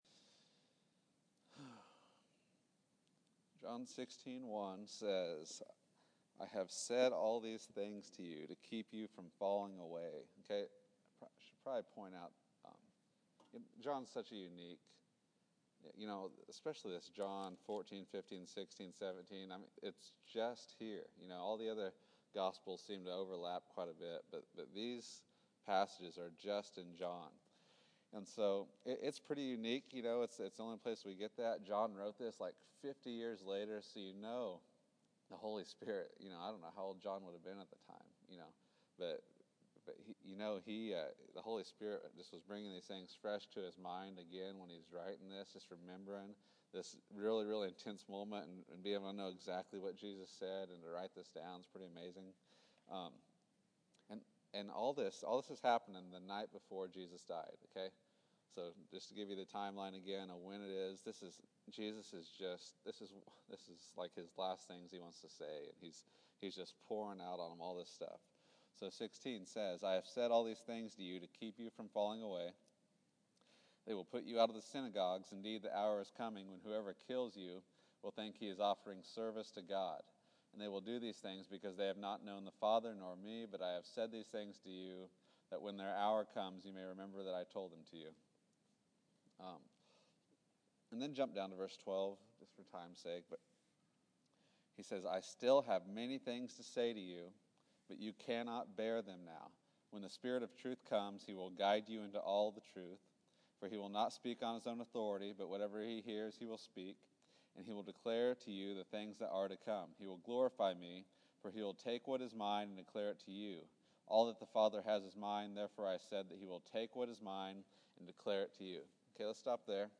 John 16 June 15, 2014 Category: Sunday School | Location: El Dorado Back to the Resource Library The work of the Holy Spirit.